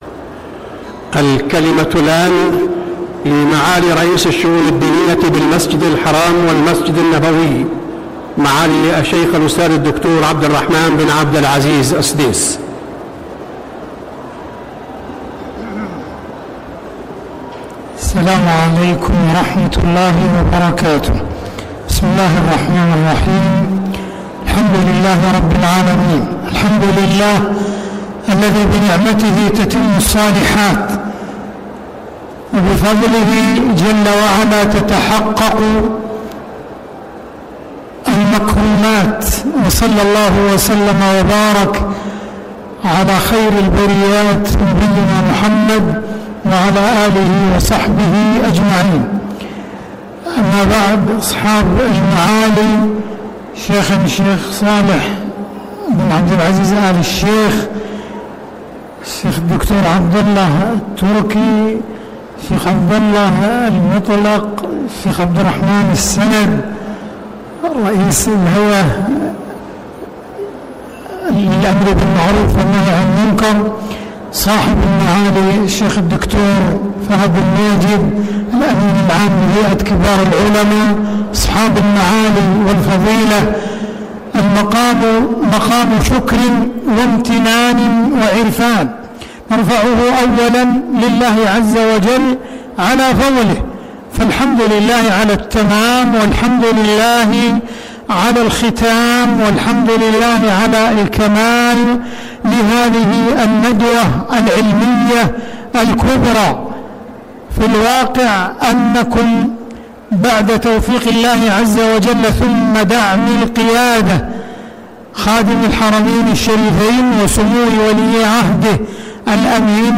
كلمة الشيخ عبدالرحمن السديس في حفل ختام ندوة الفتوى في الحرمين الشريفين 25 صفر 1446هـ > ندوة الفتوى في الحرمين الشريفين > المزيد - تلاوات الحرمين